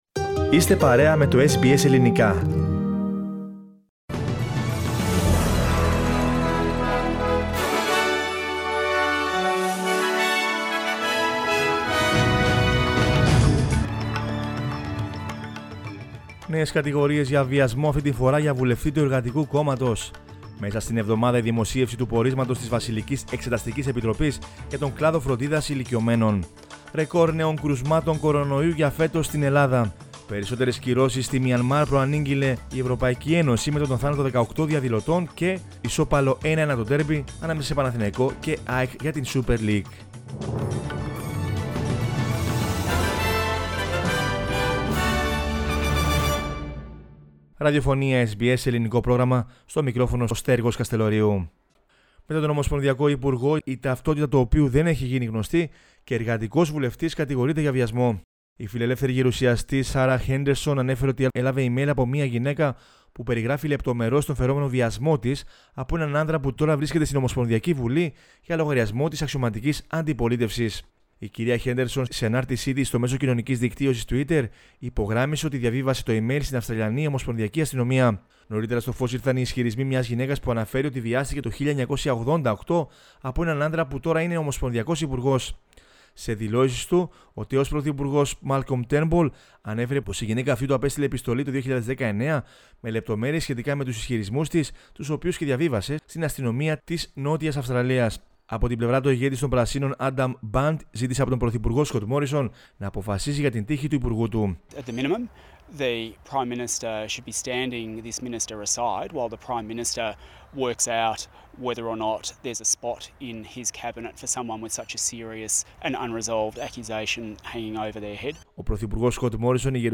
News in Greek from Australia, Greece, Cyprus and the world is the news bulletin of Monday 1 March 2021.